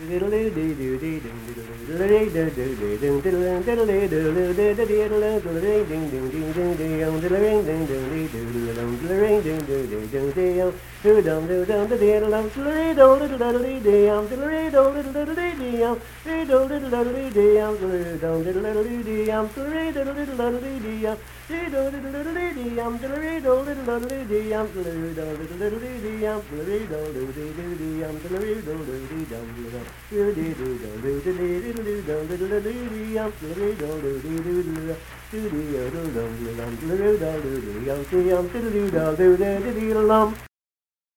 Unaccompanied vocal music
Performed in Hundred, Wetzel County, WV.
Voice (sung)